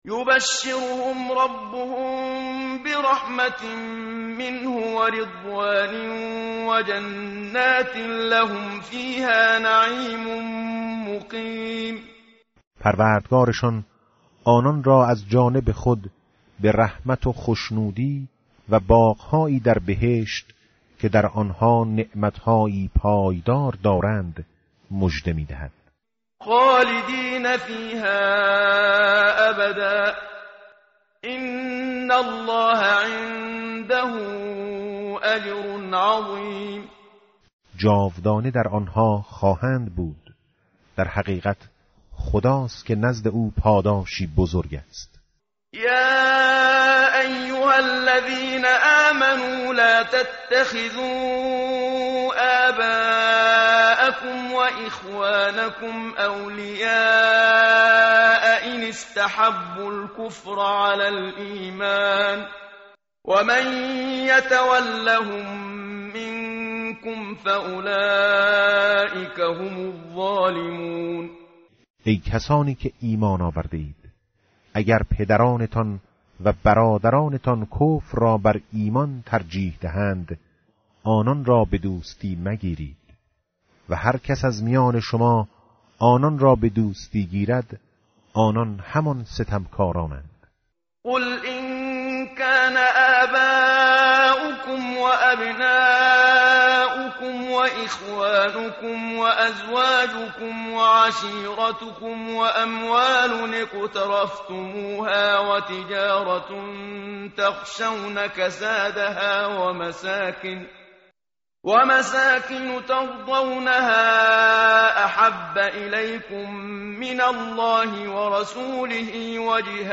tartil_menshavi va tarjome_Page_190.mp3